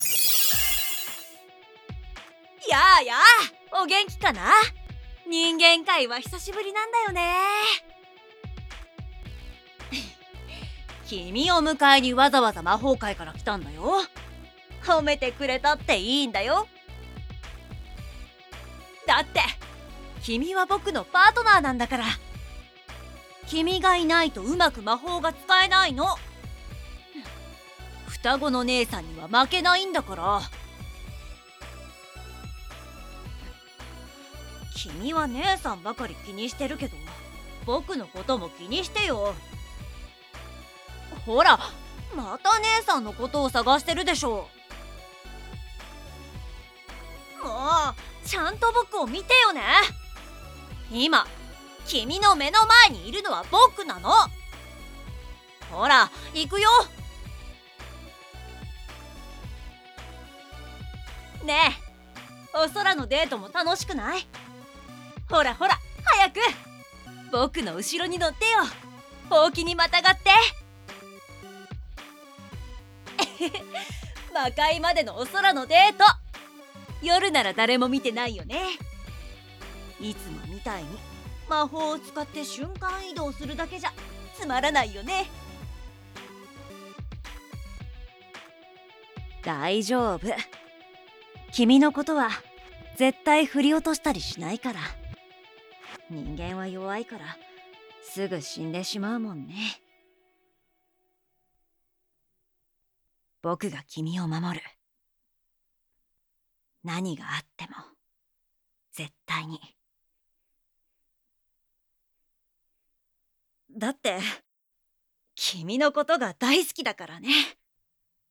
纯爱/甜蜜 幻想 纯爱 萌 治愈 皆大欢喜 女性视角 正太 女性向 乙女向